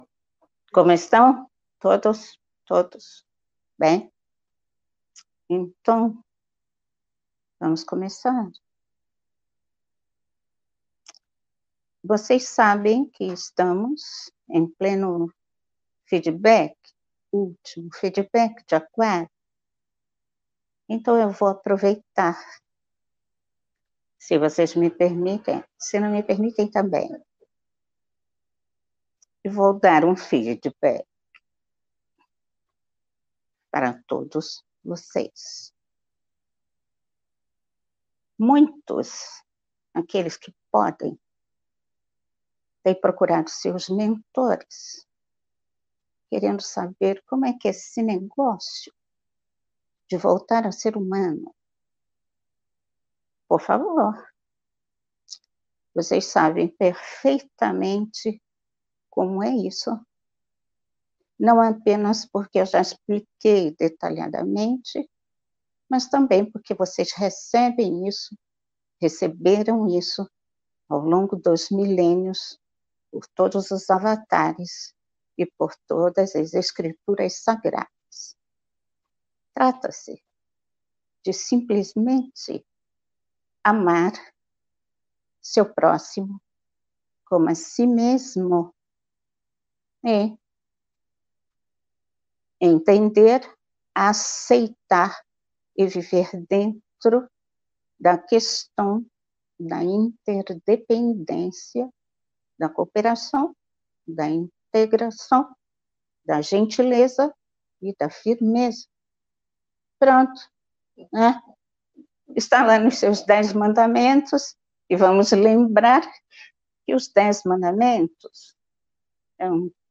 Palestra Canalizada